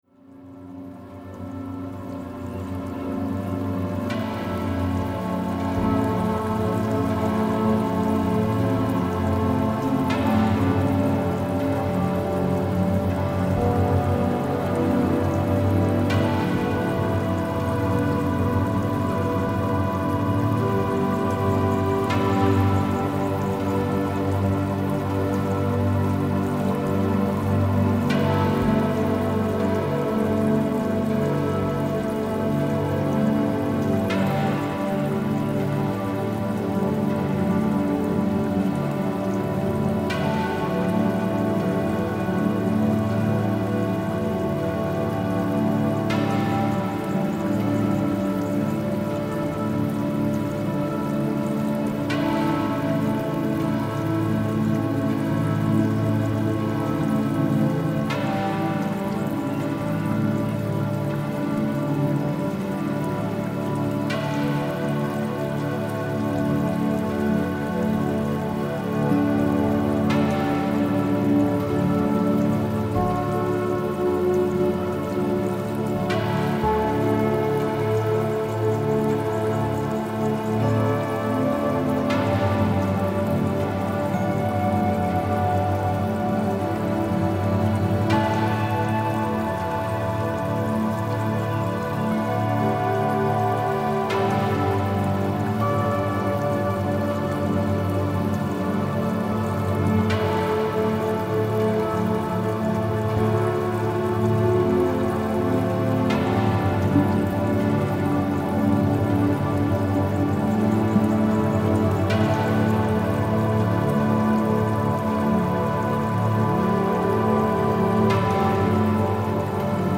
Медитация